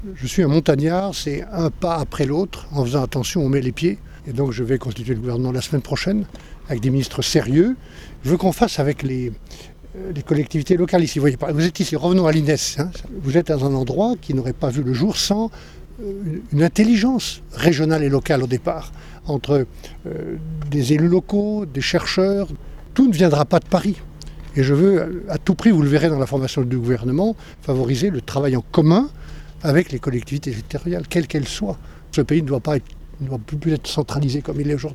Michel Barnier dit vouloir avancer pas à pas sur la formation de son gouvernement :